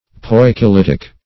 Poikilitic \Poi`ki*lit"ic\, a. (Geol.)